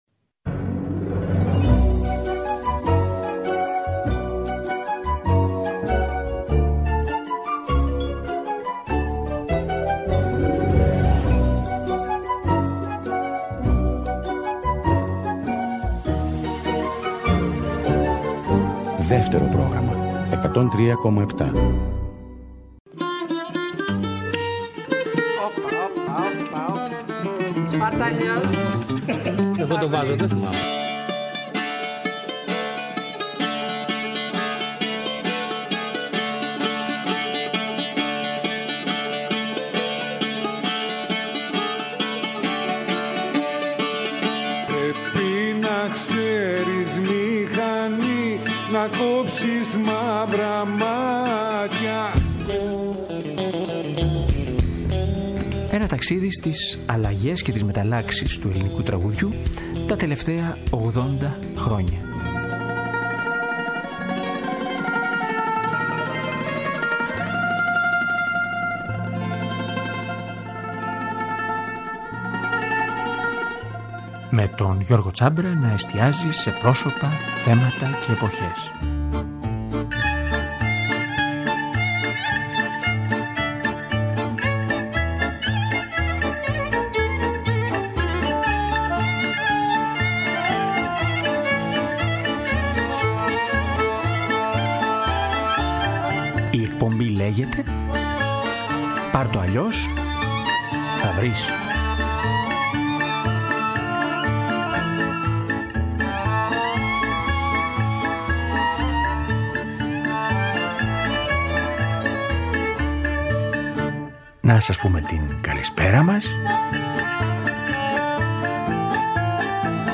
ακκορντεόν